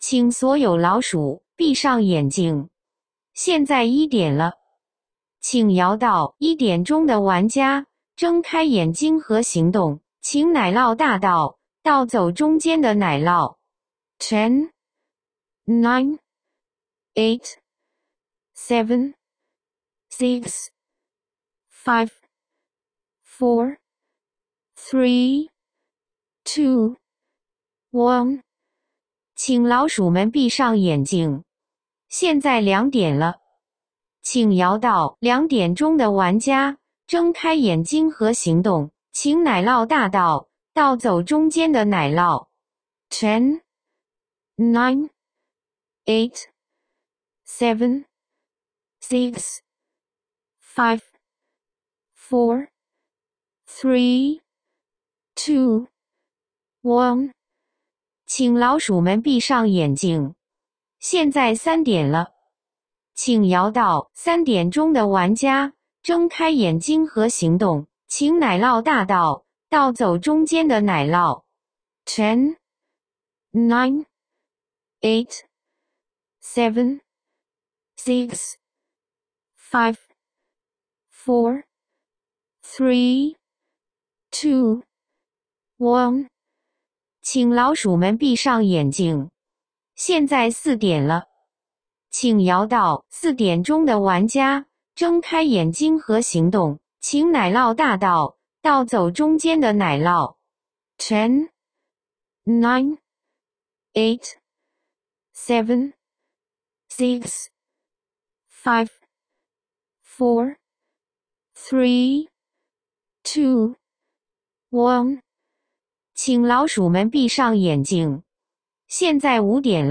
奶酪大盗桌游主持人配音，按 4–8 人局选择，可直接播放与下载。
cheese_thief_7p_host.m4a